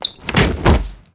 anonDumbassFall.ogg